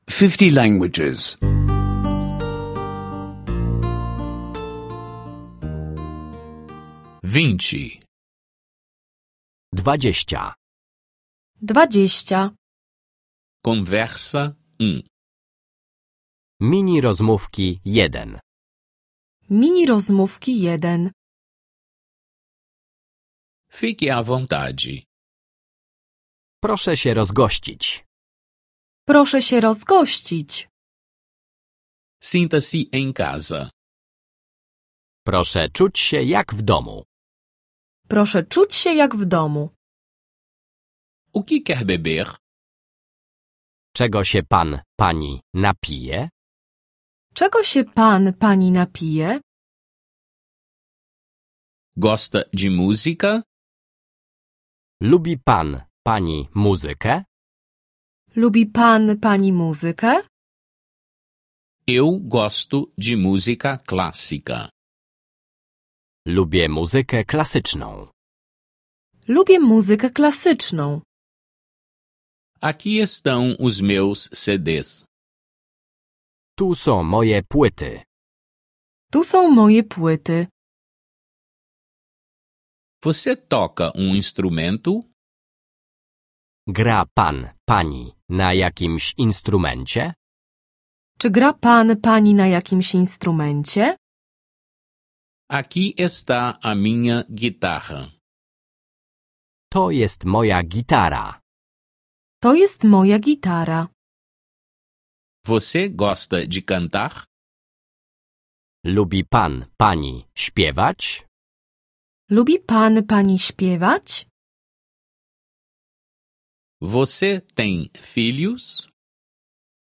Aulas de polonês em áudio — download grátis